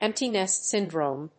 アクセントémpty nést sỳndrome